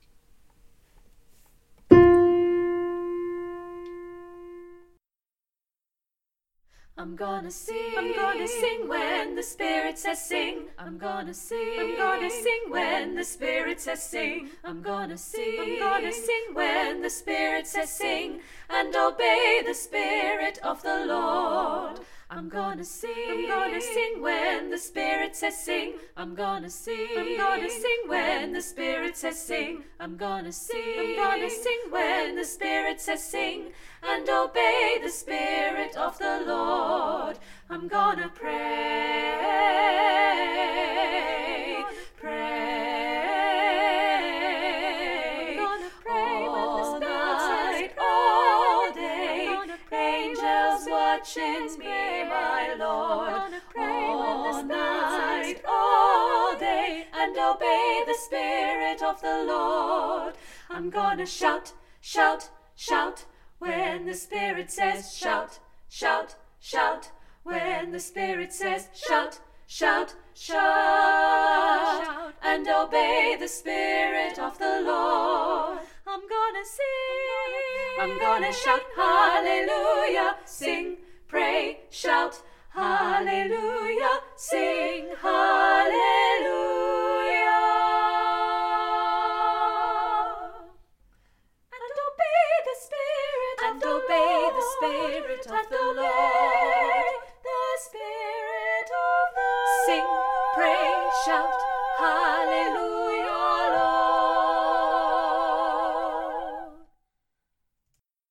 SATB I’m Gonna Sing